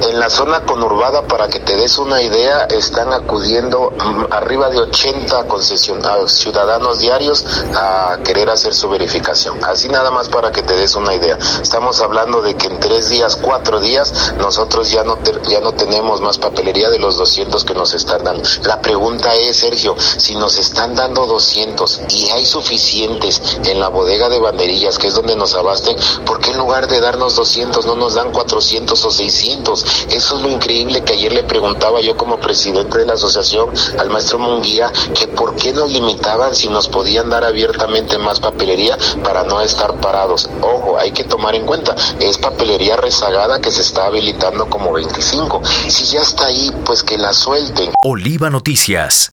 En entrevista, comentó que tuvieron acercamiento con las autoridades de la Secretaría de Finanzas y Planeación (Sefiplan), ya que les expresaron su preocupación de que no hay hologramas, apuntó que la razón por la cual no hay hologramas en los verificentros, es presuntamente por la falta de voluntad de las autoridades.